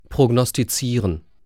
Aussprache:
🔉[proɡnɔstiˈtsiːrən]